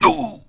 PlayerHurt1.wav